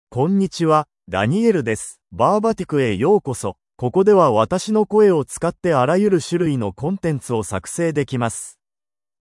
MaleJapanese (Japan)
DanielMale Japanese AI voice
Voice sample
Male
Daniel delivers clear pronunciation with authentic Japan Japanese intonation, making your content sound professionally produced.